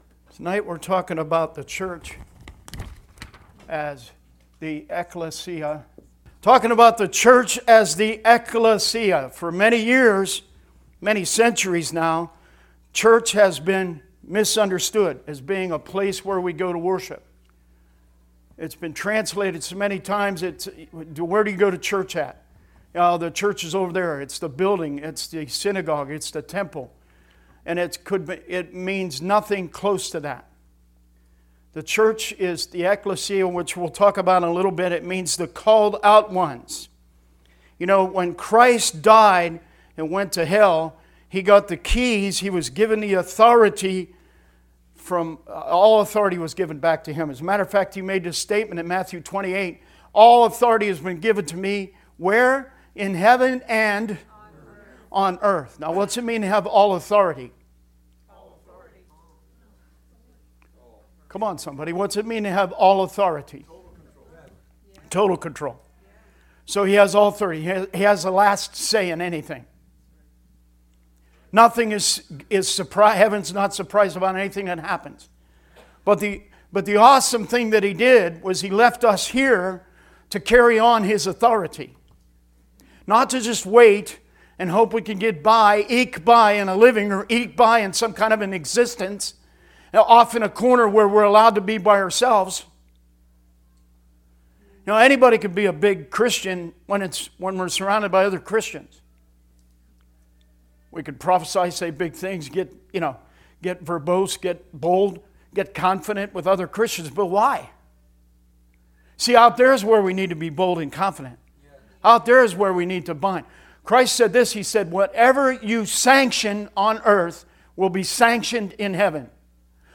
Service Type: Wednesday Teaching